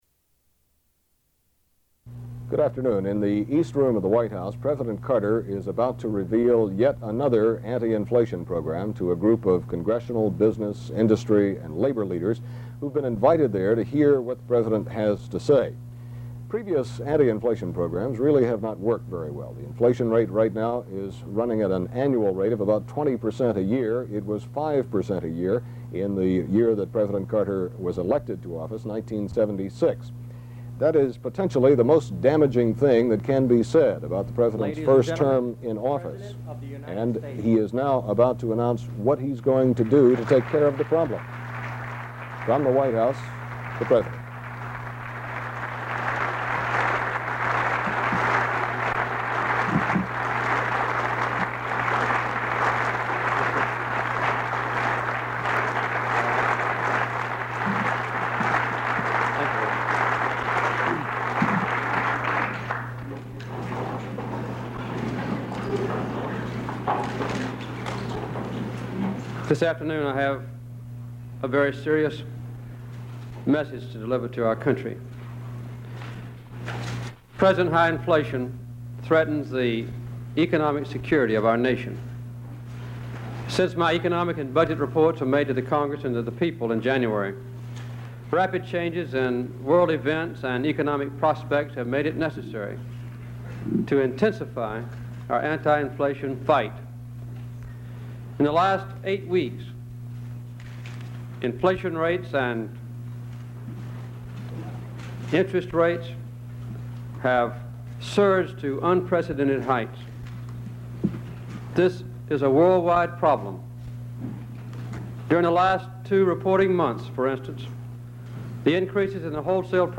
Jimmy Carter delivers a message on inflation